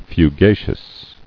[fu·ga·cious]